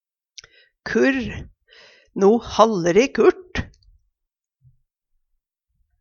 kurr - Numedalsmål (en-US)